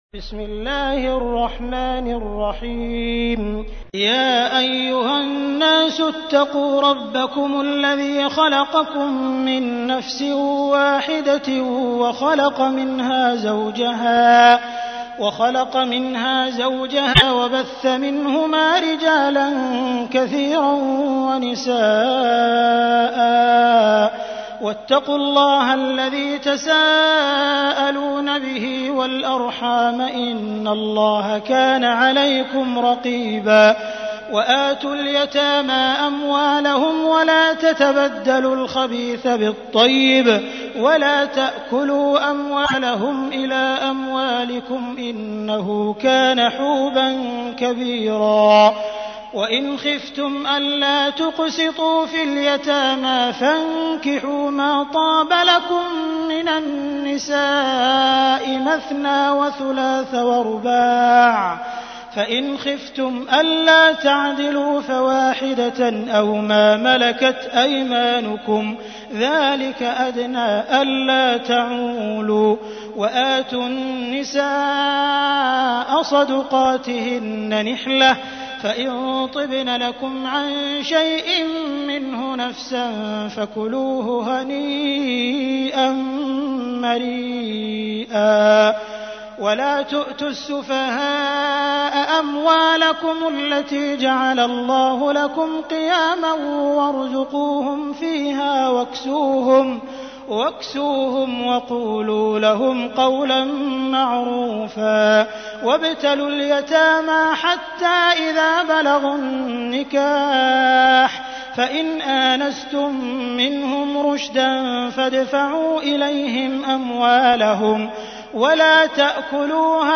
تحميل : 4. سورة النساء / القارئ عبد الرحمن السديس / القرآن الكريم / موقع يا حسين